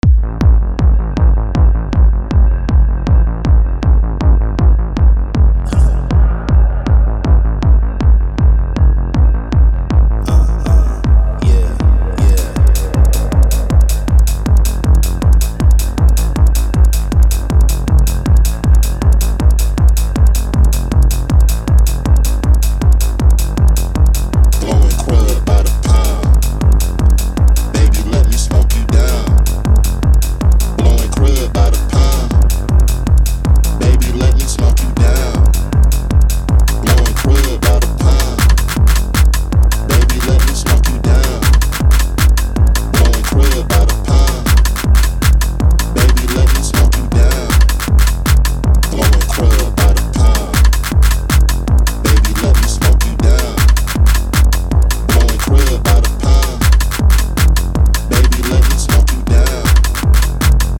ghettotech and footwork
Electro House Detroit Footwork